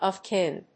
アクセントof kín